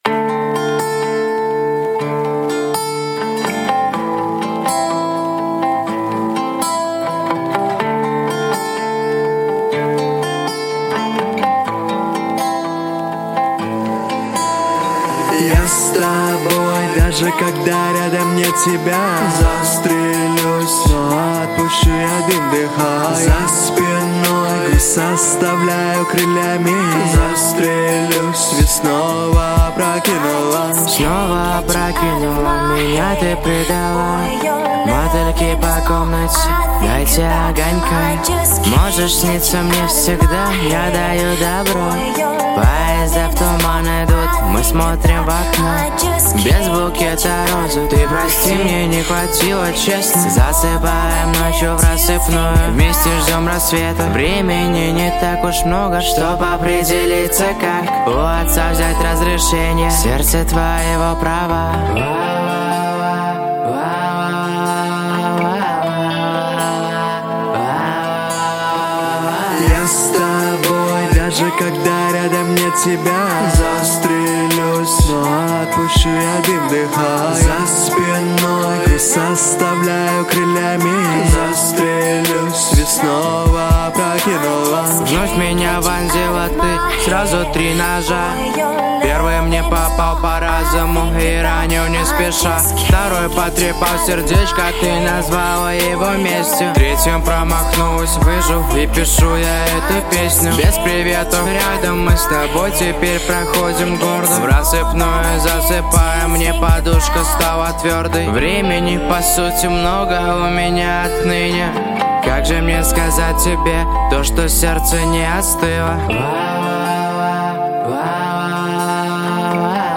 Поп-музыка
Жанр: Жанры / Поп-музыка